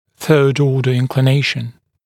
[θɜːd-‘ɔːdə ˌɪnklɪ’neɪʃn][сё:д-‘о:дэ ˌинкли’нэйшн]наклон третьего порядка